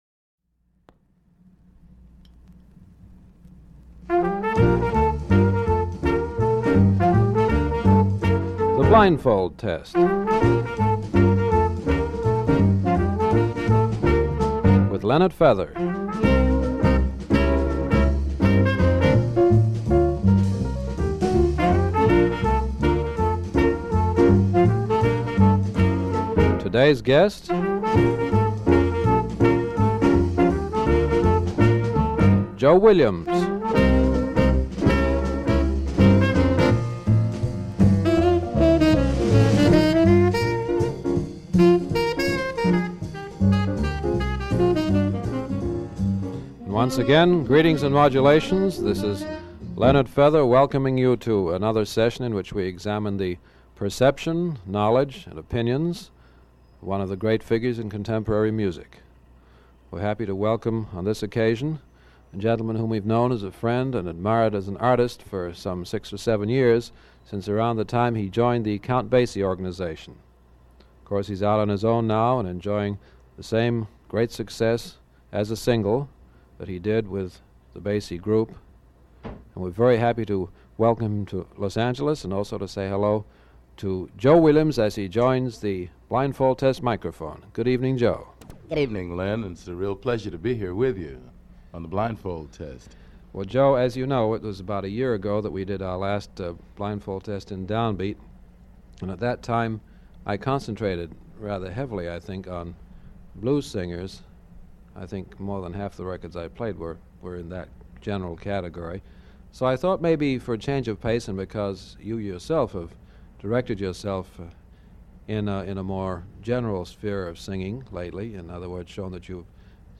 Item from Leonard Feather Collection. This is a recording of a Blindfold Test interview taken by Leonard Feather around February 1961 with Joe Williams.